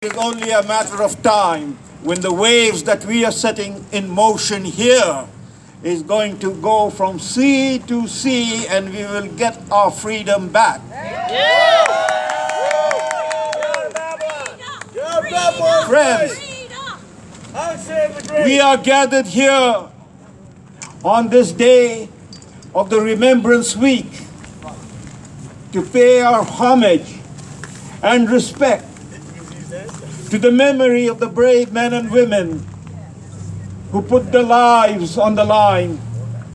The rally opened with a series of speakers – using a pickup truck as a platform – to convey a variety of messages touching on eroding freedoms, their belief the coronavirus is but a myth and the dangers associated with a COVID-19 vaccine.